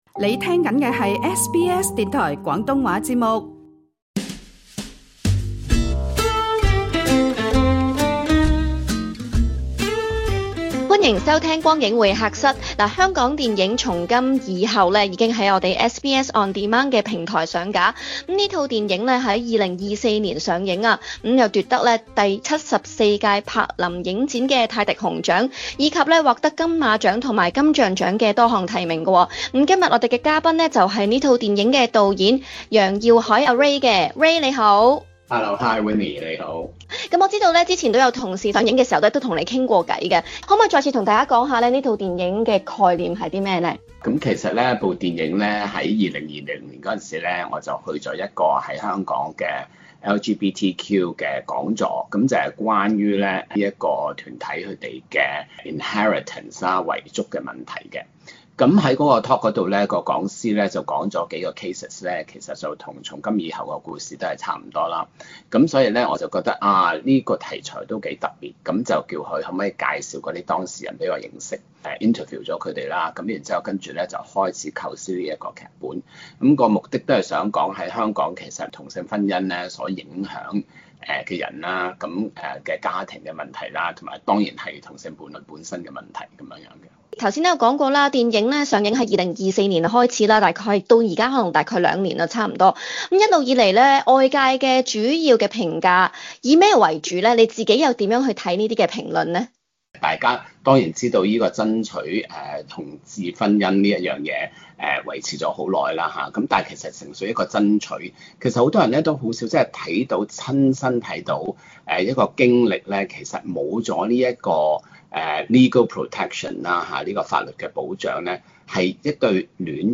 SBS廣東話邀請《從今以後》導演楊曜愷 Ray 接受訪問。